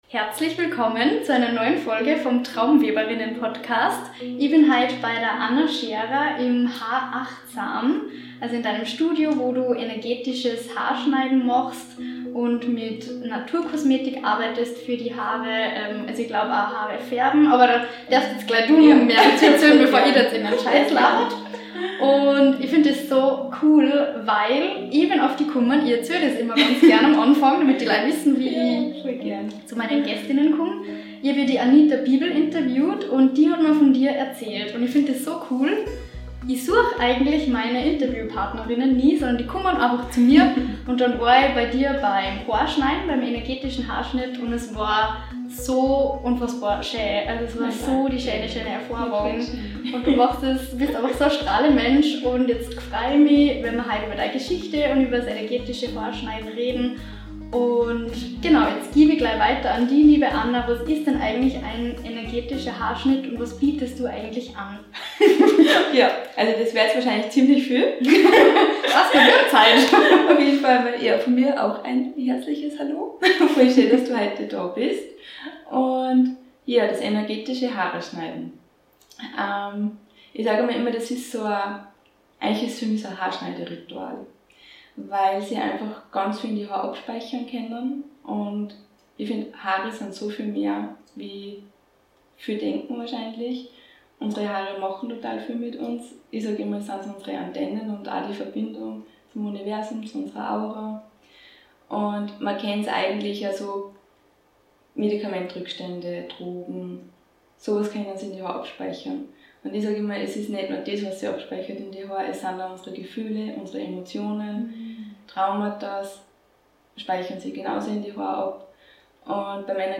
Wie unsere Haare uns beeinflussen - Interview